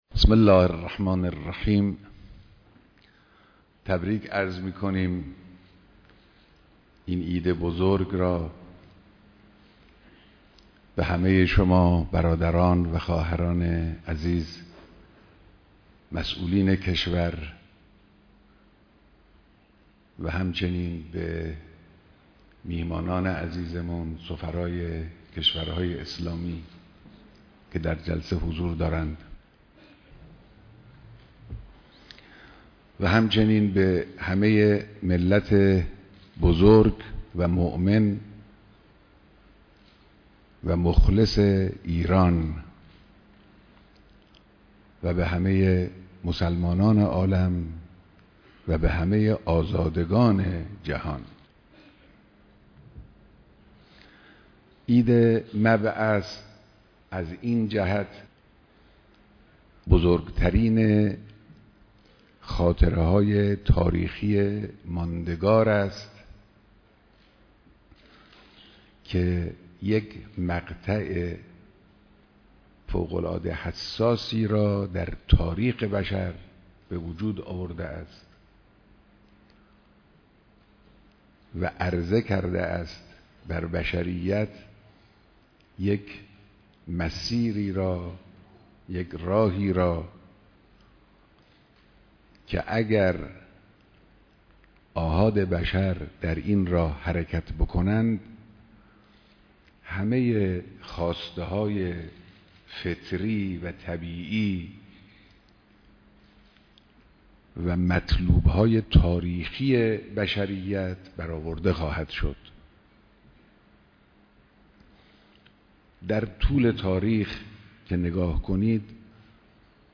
بيانات در ديدار مسئولان نظام در روز عيد مبعث‌